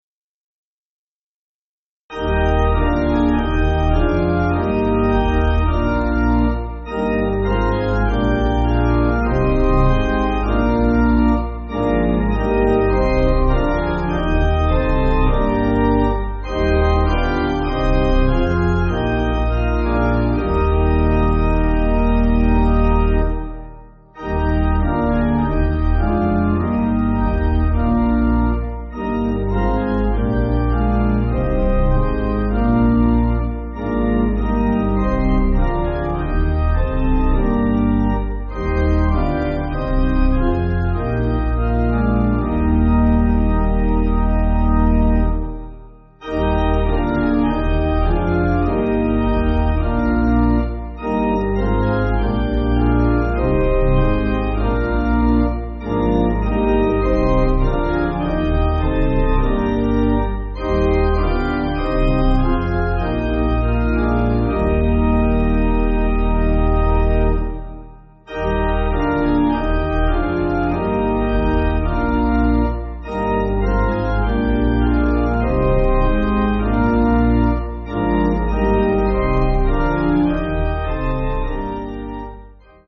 Organ
(CM)   6/Eb